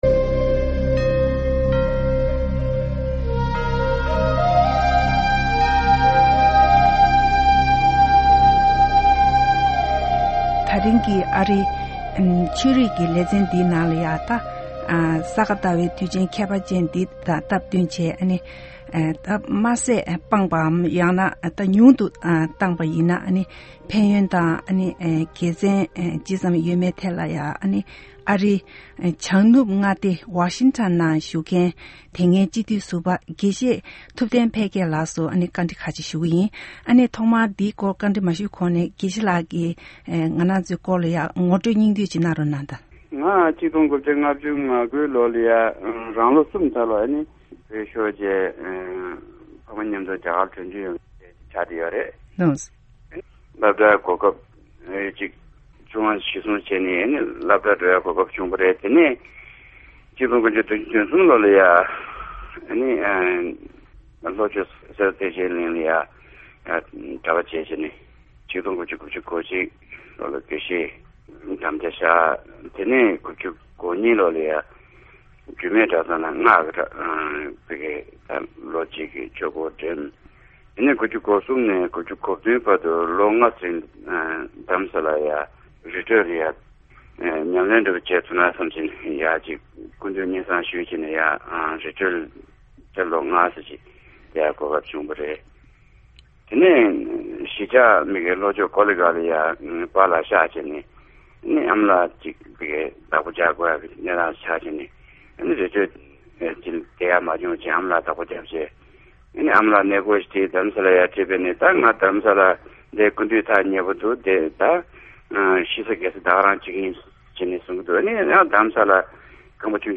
ཨ་རིའི་ནུབ་བྱང་མངའ་སྡེ་ཝ་ཤིང་ཊོན་དུ་ཡོད་པའི་སྤྱི་ཟུར་དགེ་བཤེས་ཐུབ་བསྟན་འཕེལ་རྒྱས་ལགས་ཀྱིས་དེ་སྔ་རྒྱ་གར་དུ་བོད་པའི་སྤྱི་ཚོགས་ཀྱི་ནང་དམར་ཟས་སྤོང་རྒྱུའི་ལས་འགུལ་སྤེལ་བའི་སྐབས་ཉམས་མྱོང་ཇི་བྱུང་གནས་འདྲི་ཞུས་པ་ཞིག་གསན་གྱི་རེད།